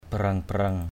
/ba-rʌŋ-ba-rʌŋ/ (cv.) bireng-bireng b{r$-b{r$